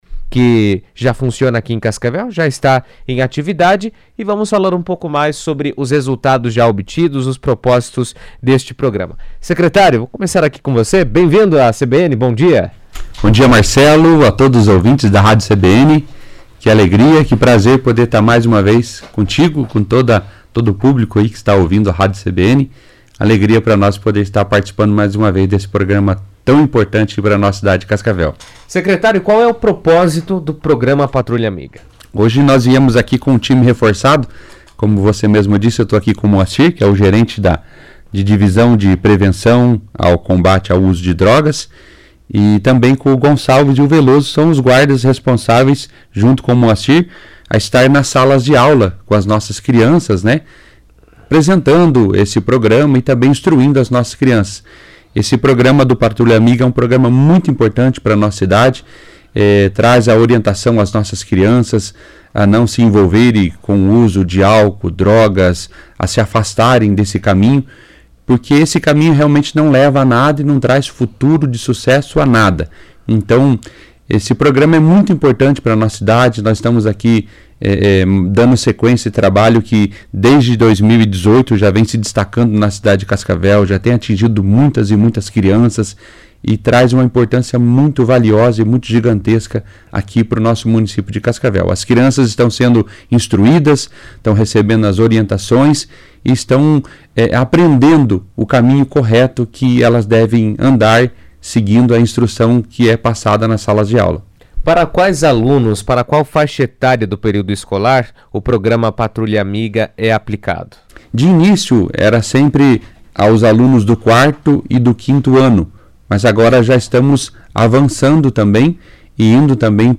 Em funcionamento desde 2018, o Programa Patrulha Amiga atua na prevenção e conscientização de estudantes da rede municipal sobre os riscos do uso de drogas, promovendo ações educativas e palestras nas escolas. Em entrevista à CBN